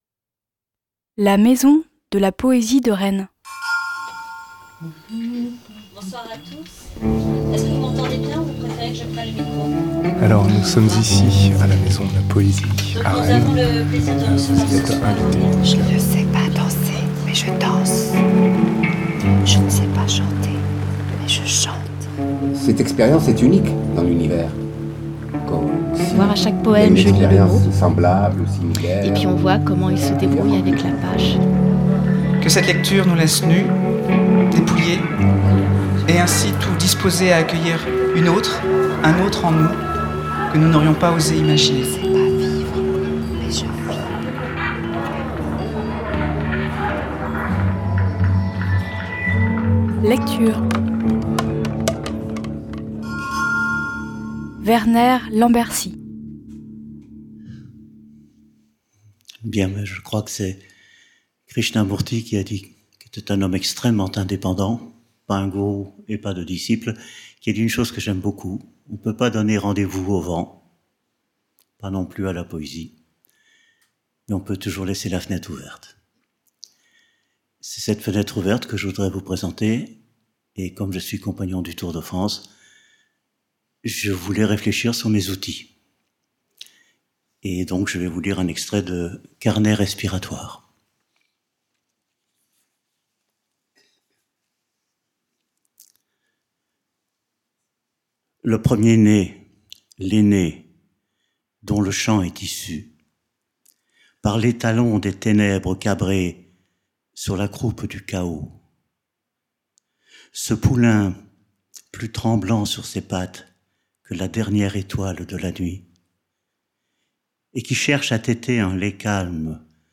Lecture à l’occasion de l’édition 2012 du festival des Polyphonies organisé par la maison de la Poésie de Rennes. Prise de son et mixage, Radio Univers. http